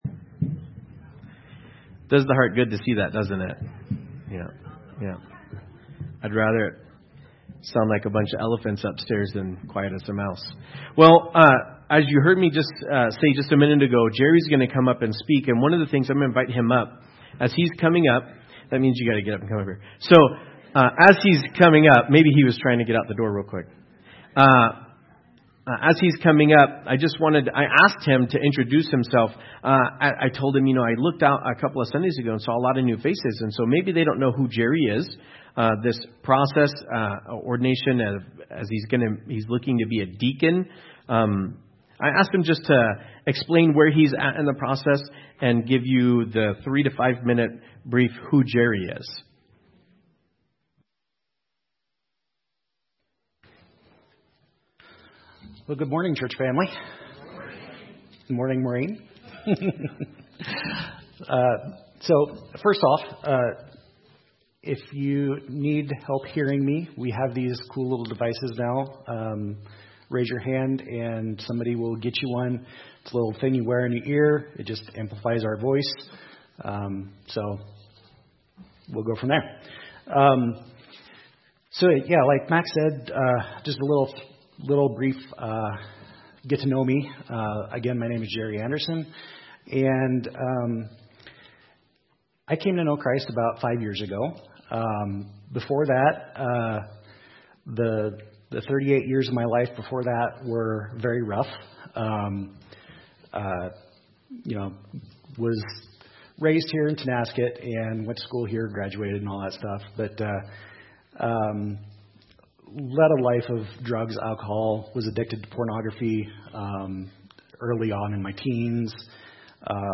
Audio Sermons | Tonasket Free Methodist Church